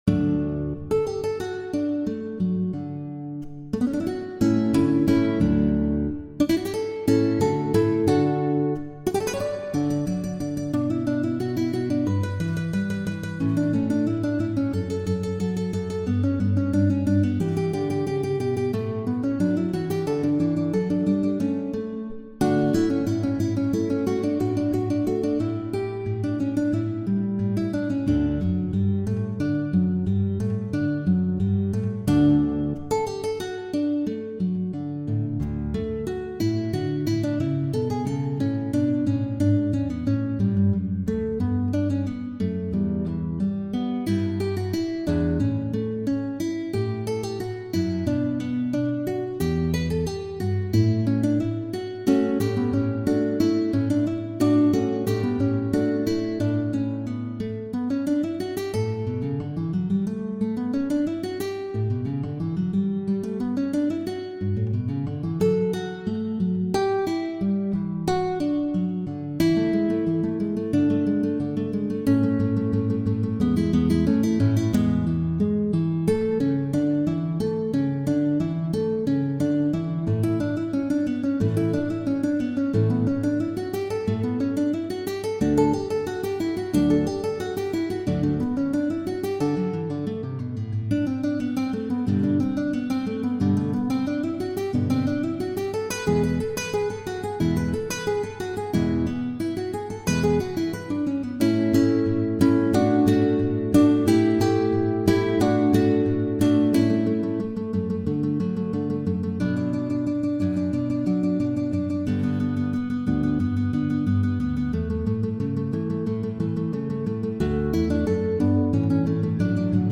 Concerto-en-re-majeur-1er-mouvement-Allegro.mp3